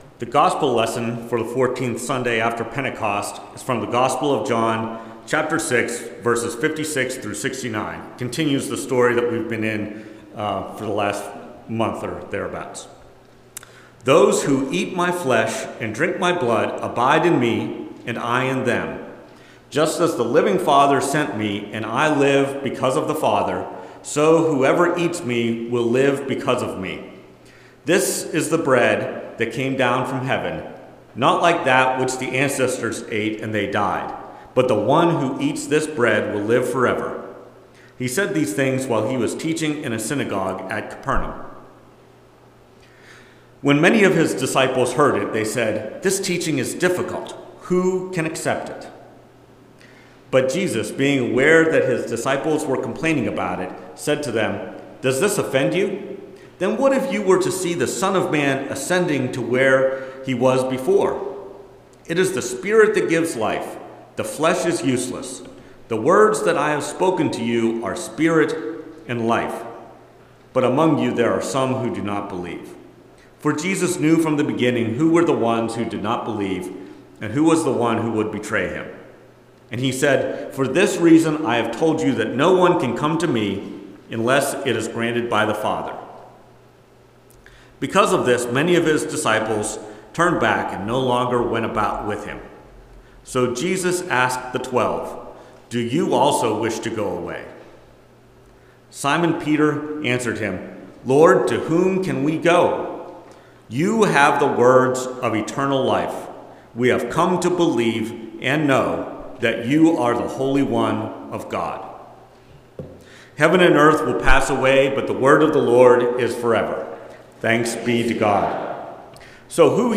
Preached at First Presbyterian Church of Rolla on August 25, 2024. Based on John 6:56-69.